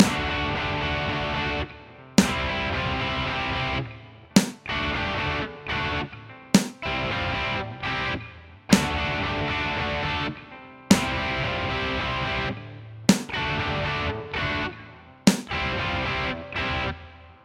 今日のフレーズは1小節に頭1回だけ鳴らすパターンなので、ちょっとリズムを取りづらい。 が、フレーズ自体はシンプルなのでさくっと終わった！